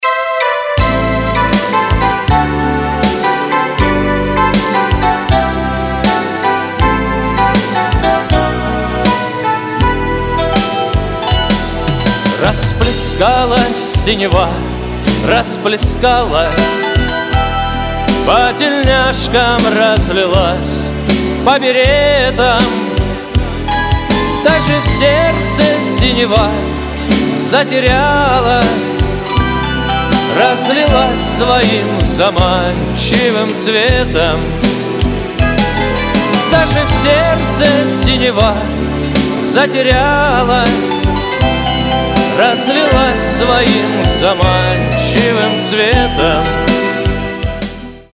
• Качество: 256, Stereo
гитара